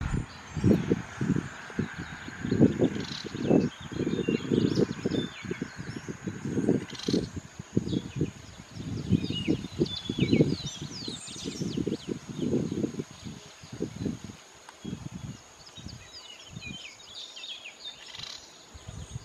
Fiofío Plomizo (Elaenia strepera)
Nombre en inglés: Slaty Elaenia
Localidad o área protegida: Las Juntas
Condición: Silvestre
Certeza: Observada, Vocalización Grabada